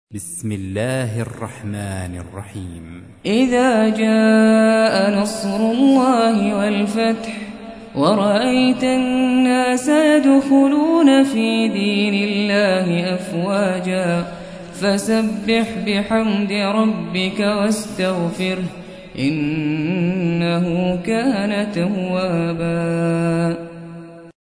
110. سورة النصر / القارئ